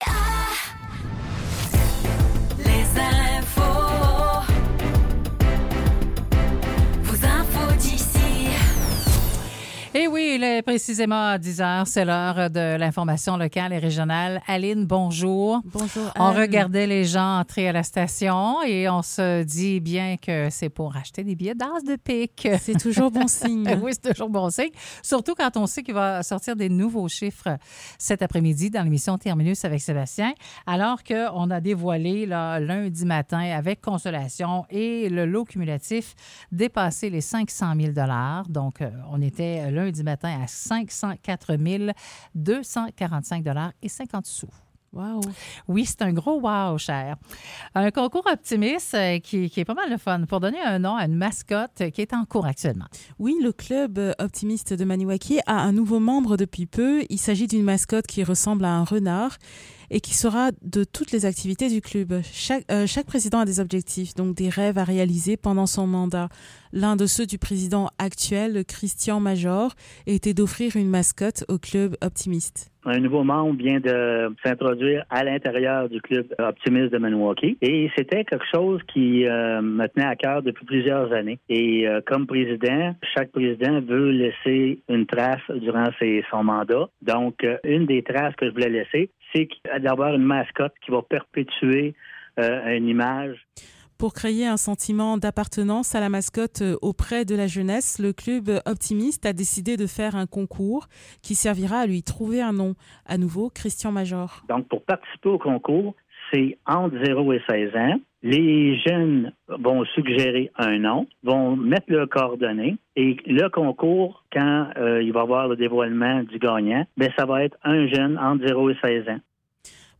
Nouvelles locales - 5 mars 2024 - 10 h